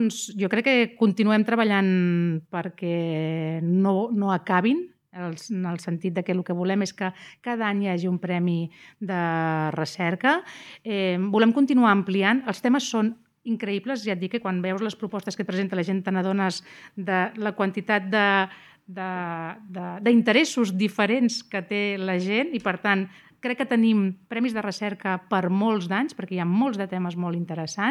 Maria Blanco assegura que des de l’Ajuntament es continuaran impulsant els Premis Jordi Comas, entre altres qüestions, perquè existeixen molts temes interessants a tractar sobre Castell d’Aro, Platja d’Aro i S’Agaró.